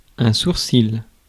ÄäntäminenFrance (Paris):
• IPA: [ɛ̃.suʁ.sil]